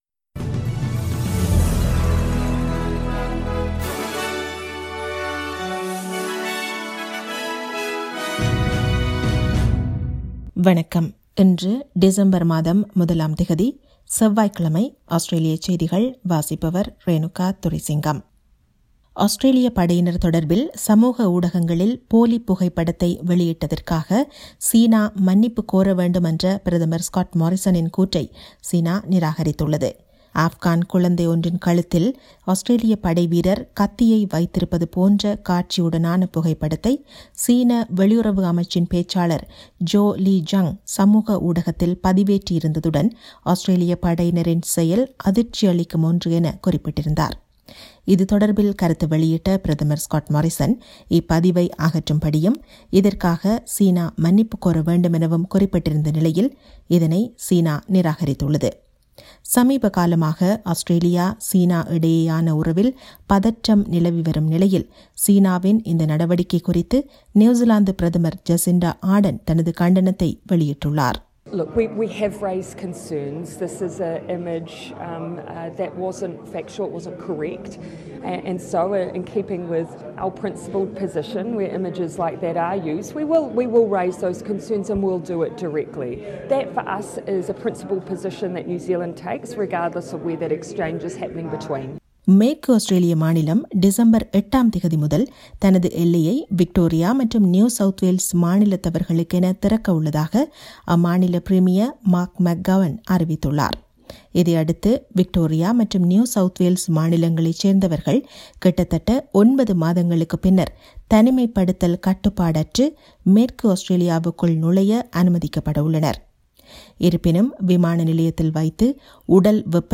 Australian news bulletin for Tuesday 01 December 2020.